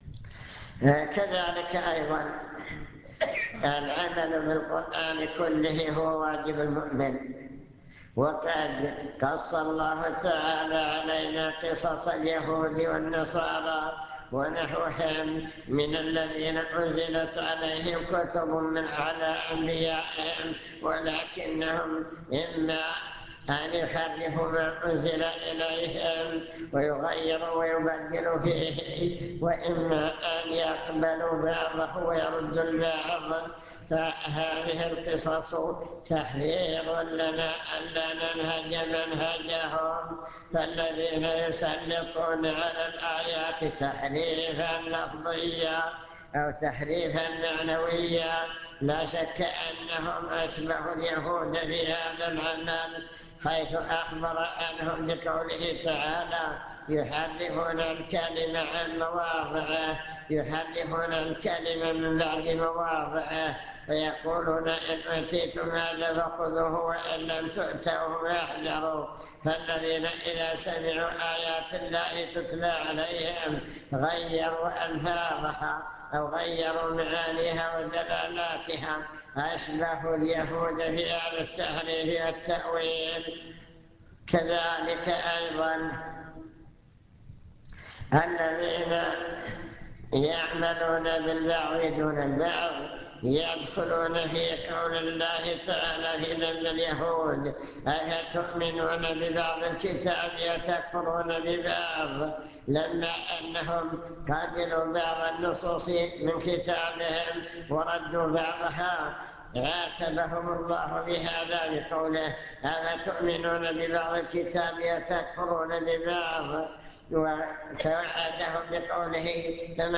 المكتبة الصوتية  تسجيلات - محاضرات ودروس  محاضرة عن القرآن والسنة